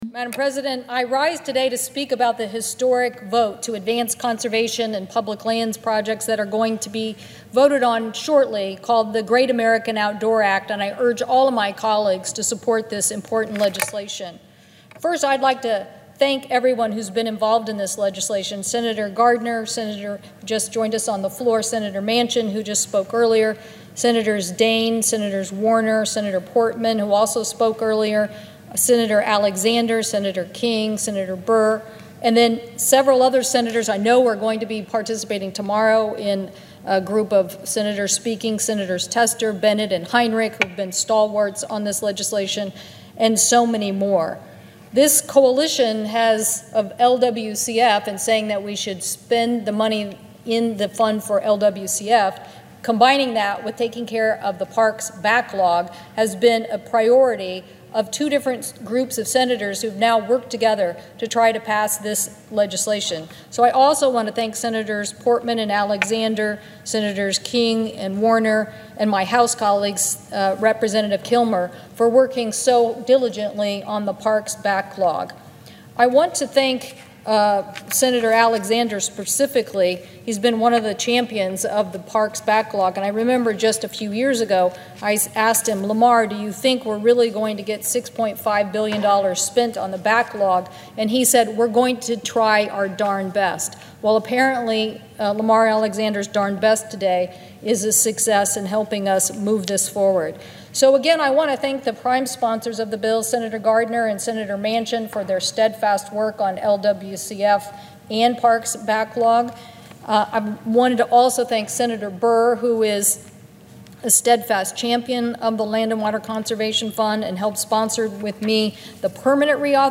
great-american-outdoors-act-floor-speech-audio&download=1